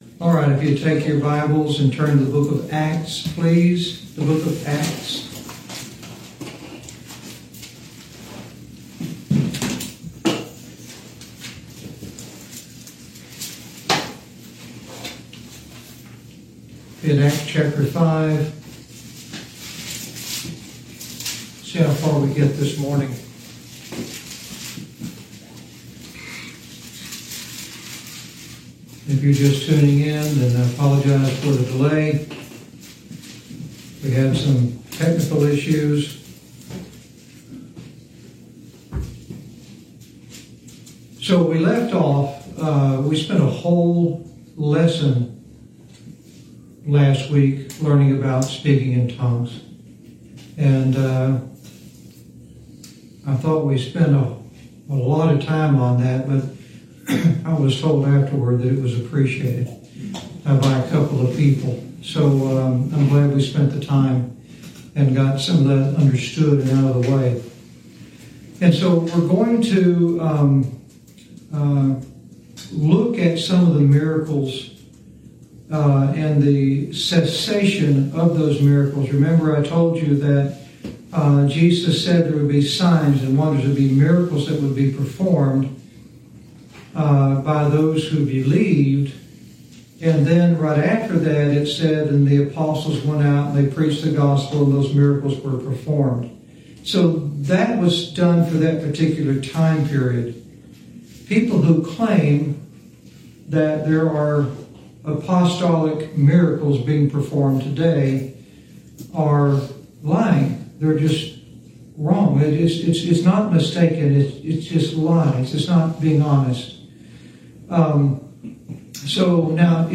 Lesson 3